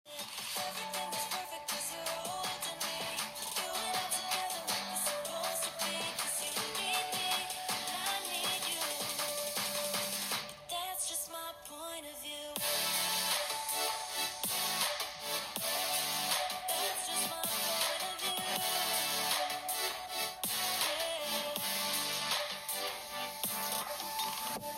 肝心の音については、"物足りない"印象です。
▼Google Pixel 7 Proのステレオスピーカーの音はこちら！
一方で、音のクリアさが同価格帯モデルに比べやや物足りない印象。
音のクリアさが物足りず、雑味を感じます。